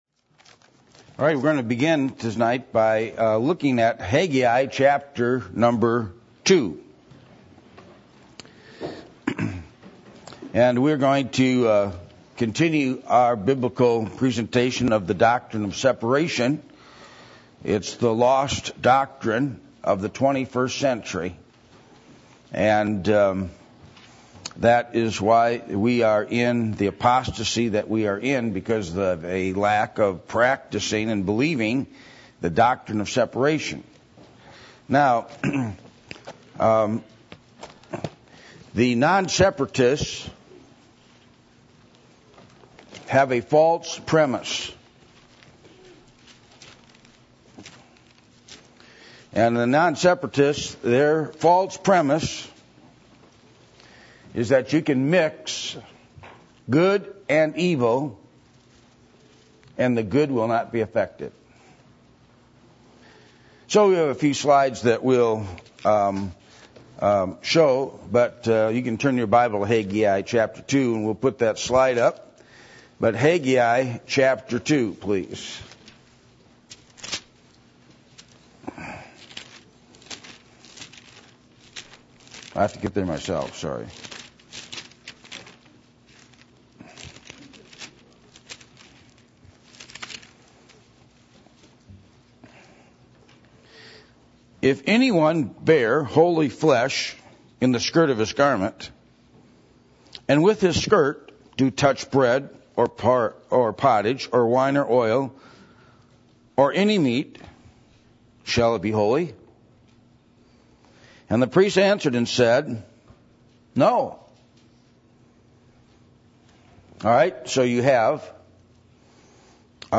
Haggai 2:12-13 Service Type: Sunday Evening %todo_render% « Reward